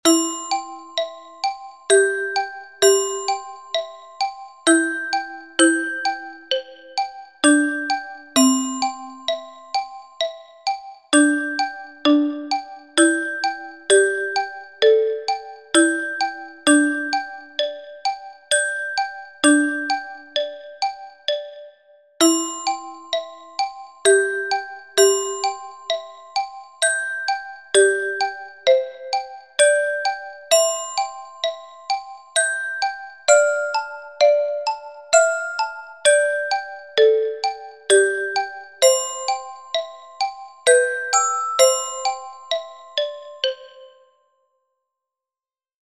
Marimba
Marimba, xylophone, glockenspiel score
2o_mov_SINF_NUEVO_MUNDO_-_MARIMBA.mp3